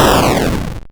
missile.wav